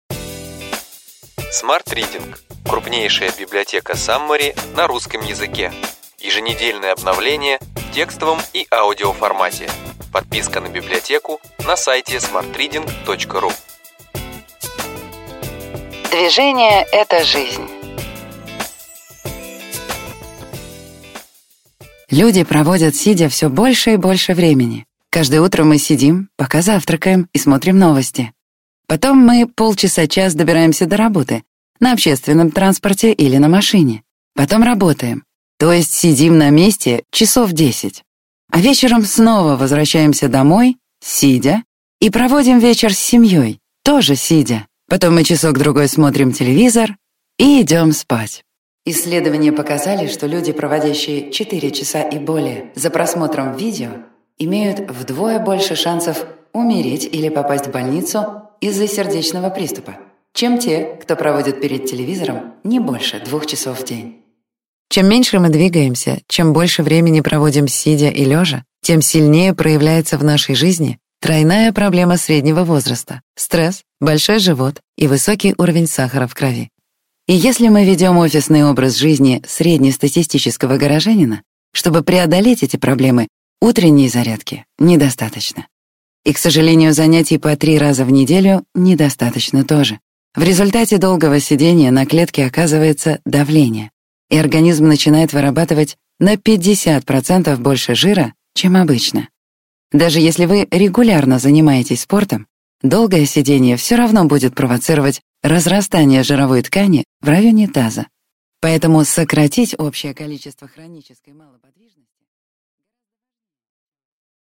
Аудиокнига Ключевые идеи: Движение. Как сделать физическую активность частью жизни и не терять форму | Библиотека аудиокниг